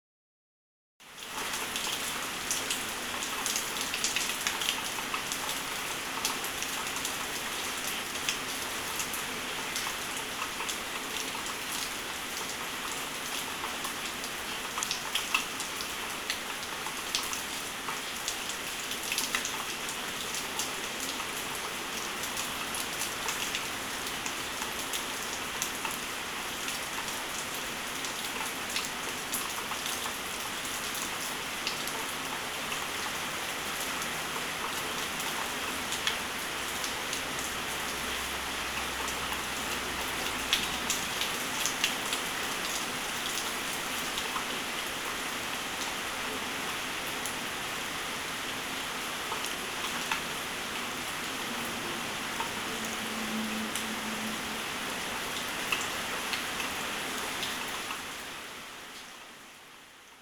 2. Deszczowe odgłosy – zabawa rozwijająca percepcję słuchową.
Deszcz.mp3